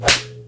mob_take_damage.wav